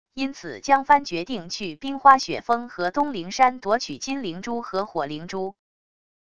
因此江帆决定去冰花雪峰和东灵山夺取金灵珠和火灵珠wav音频生成系统WAV Audio Player